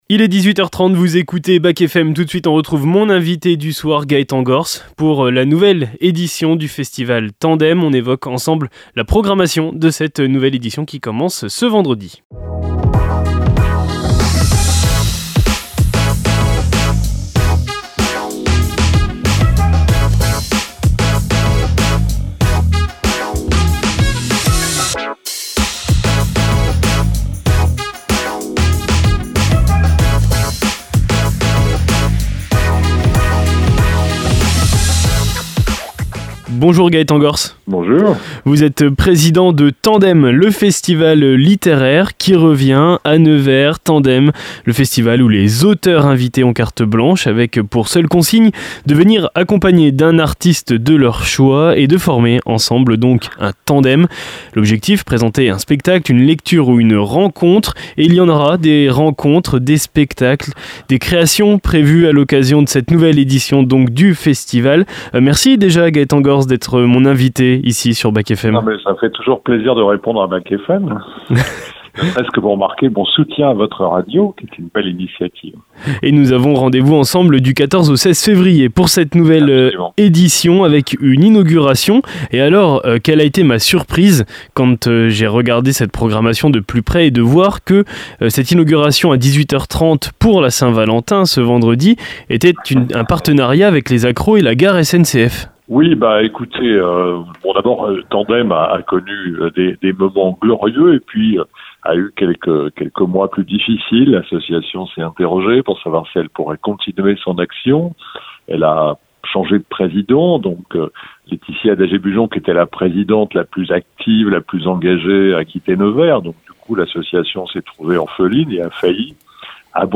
Gaëtan Gorce, Président de l'association Tandem présente la nouvelle édition du festival littéraire qui se déroulera du 14 au 16 février 2025 à Nevers